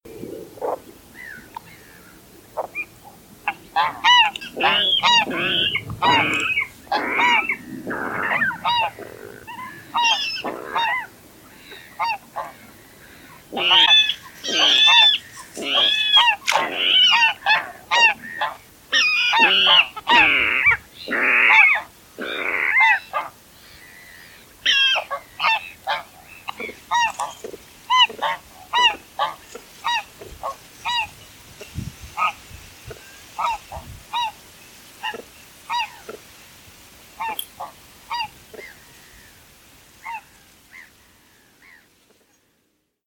waterral
🔭 Wetenschappelijk: Rallus aquaticus
waterral_roep.mp3